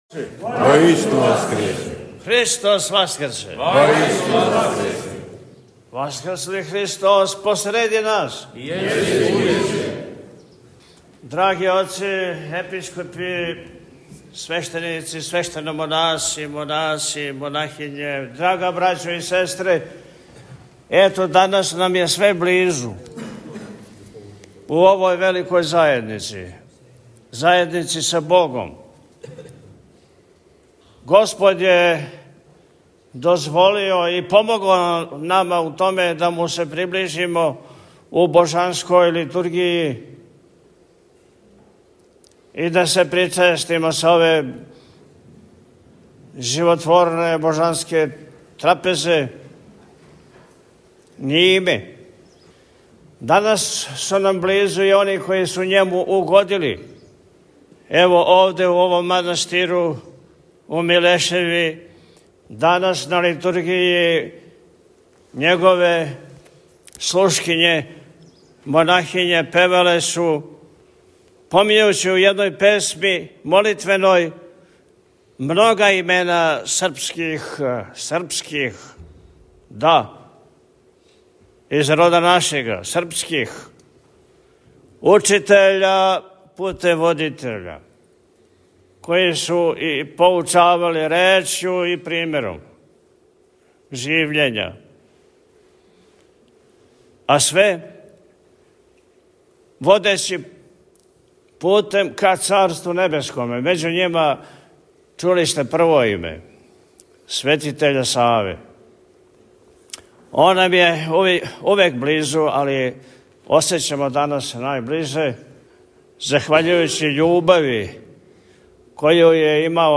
Празник Преноса моштију Светог Саве прослављен у манастиру Милешеви